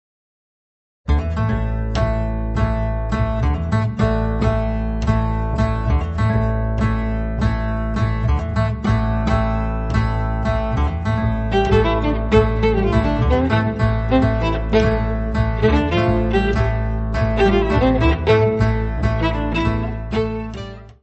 contrabaixo
violino
guitarra.
Área:  Jazz / Blues